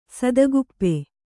♪ sadaguppe